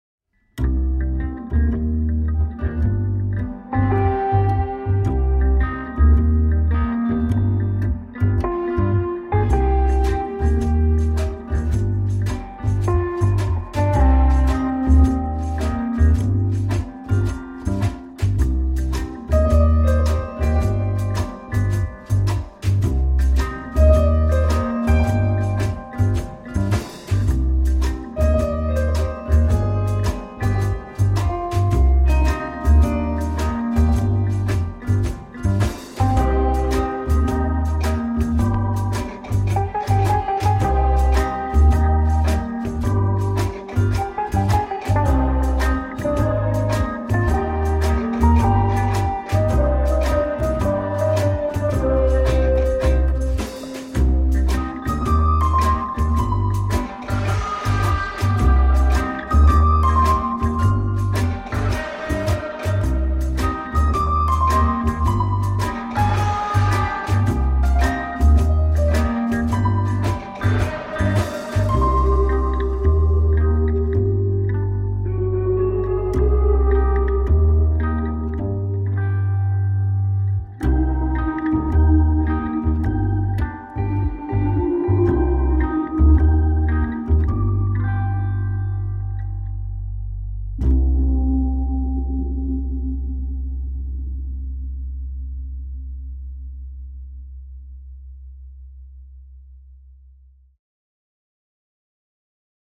créant une ambiance musicale étrange et tendue
Drones, drones, drones.
et un score synthé syncopé particulièrement réussi
Quelques morceaux acoustiques un brin jazzy aussi.